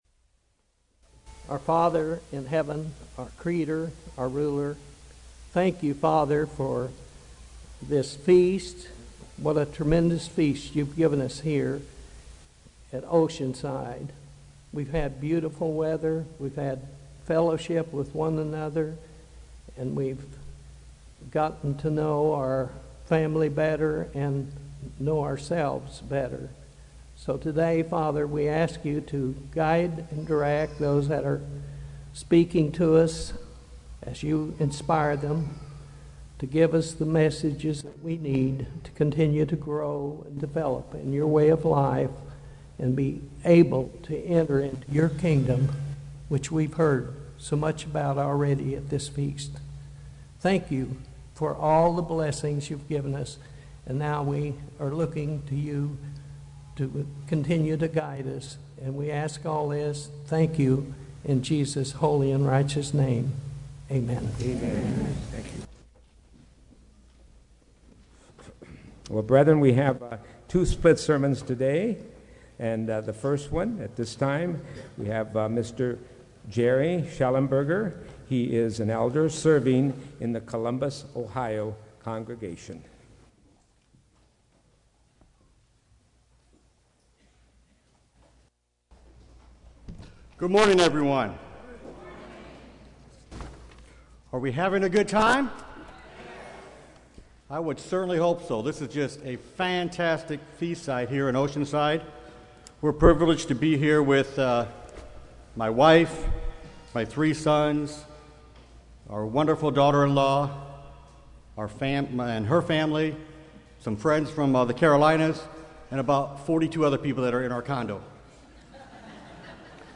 This sermon was given at the Oceanside, California 2014 Feast site.